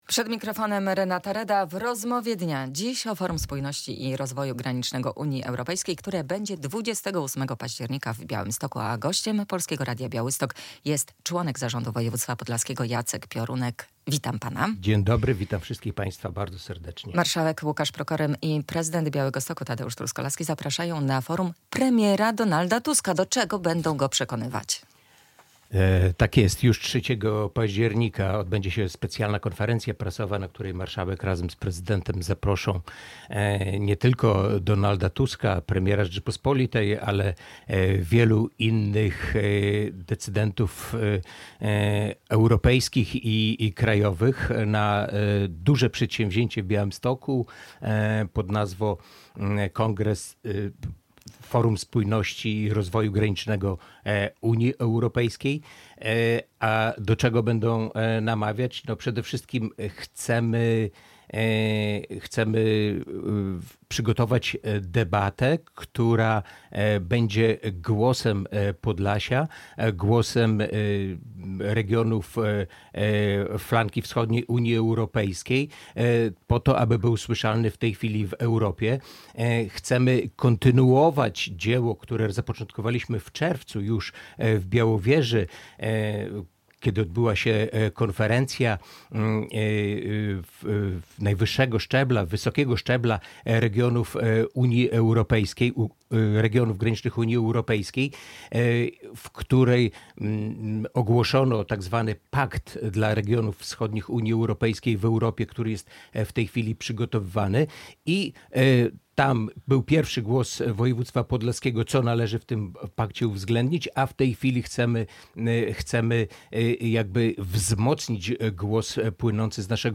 Jacek Piorunek - członek zarządu województwa podlaskiego